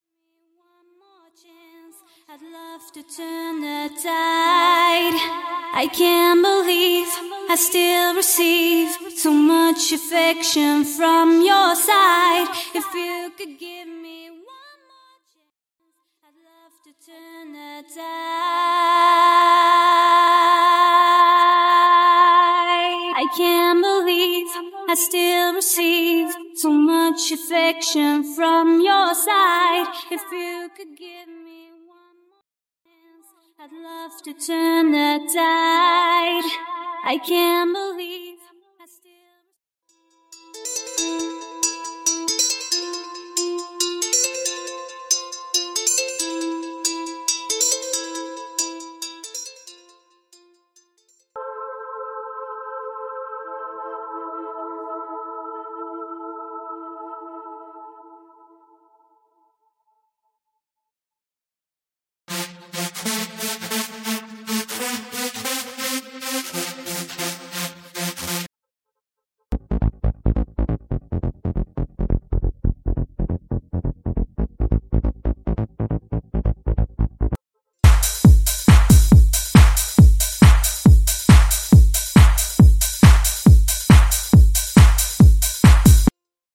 (Percussion & Drums Stem)
(Synths, Keys & SFX Stem)